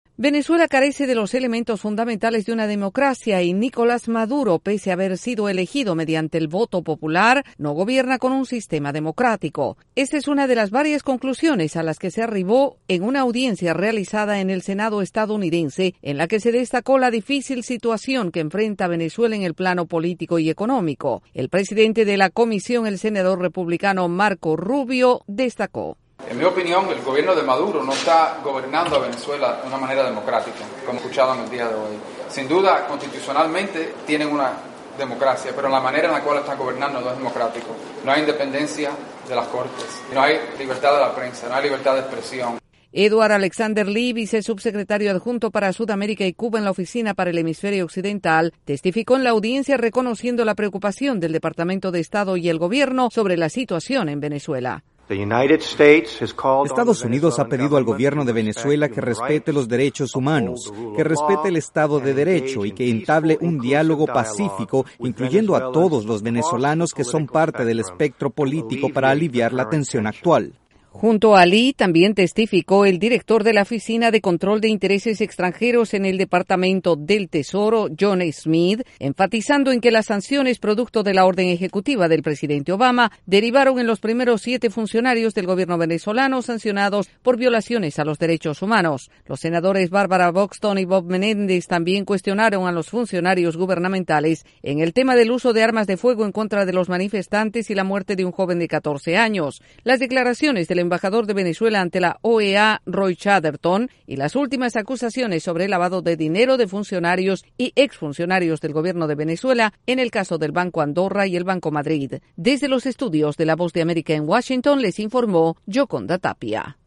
Una audiencia en el Senado estadounidense expresa preocupación por los valores democráticos en Venezuela. El informe desde la Voz de América en Washington DC